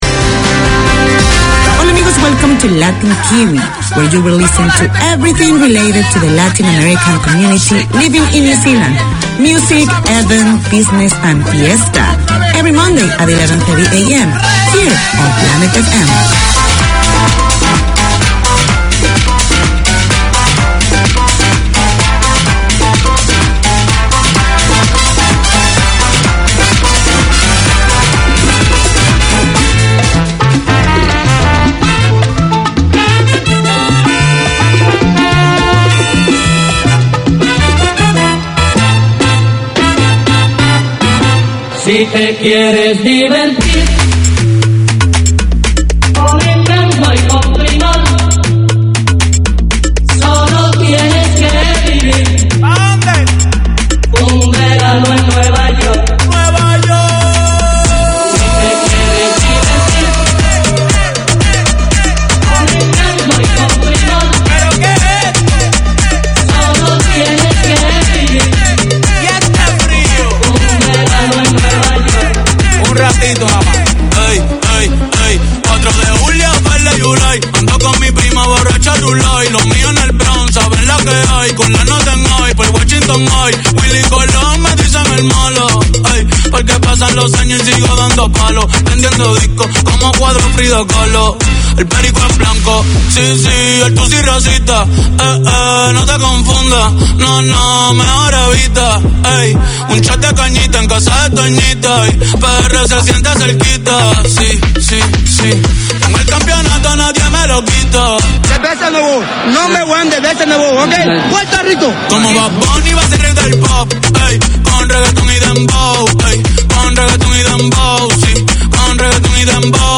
Radio made by over 100 Aucklanders addressing the diverse cultures and interests in 35 languages.
Latin Kiwi 4:25pm WEDNESDAY Community magazine Language: English Spanish Bienvenidos a todos!